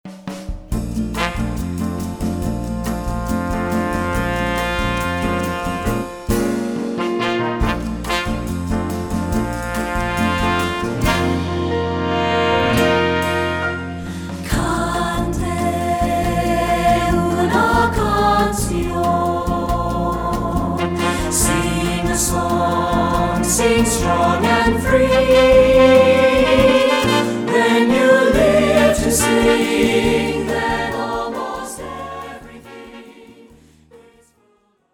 Instrumentation: Choral Pax (full score)